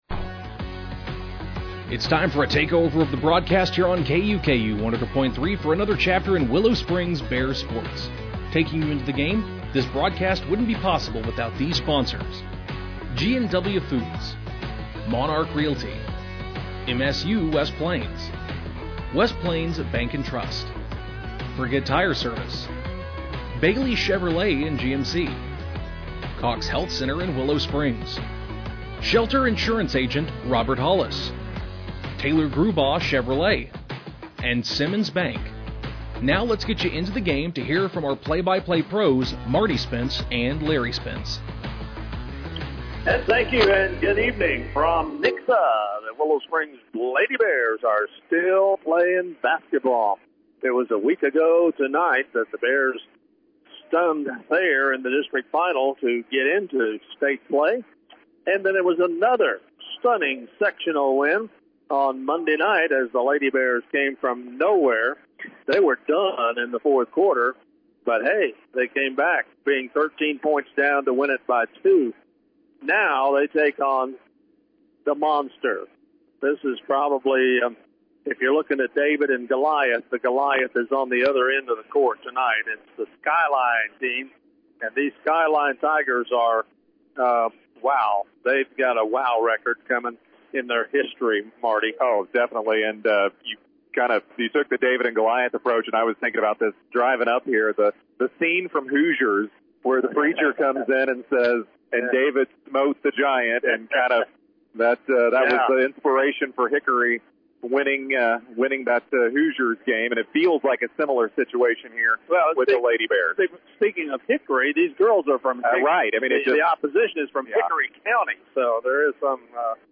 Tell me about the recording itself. The Willow Springs Lady Bears took on the 23-6, Skyline Lady Tigers in a State Quarterfinal Matchup at Nixa High School in Nixa, Missouri on Friday, March 6th, 2026.